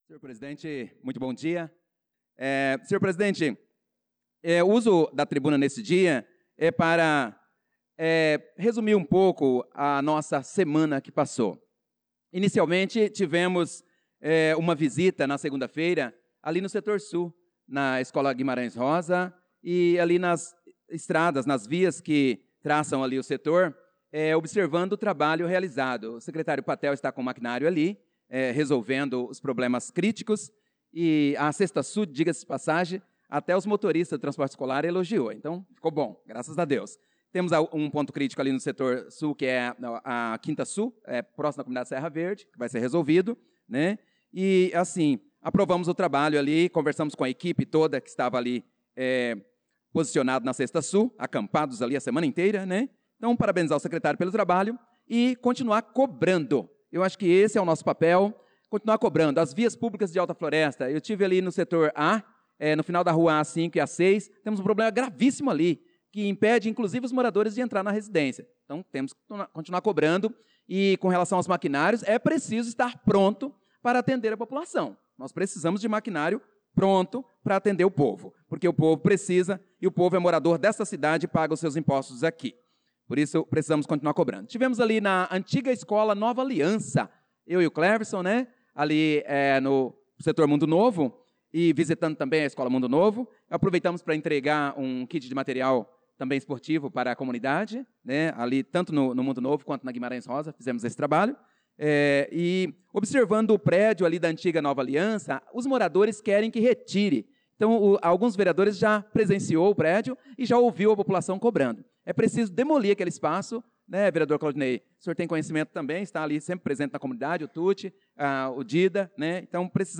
Pronunciamento do vereador Prof. Nilson na Sessão Ordinária do dia 25/03/2025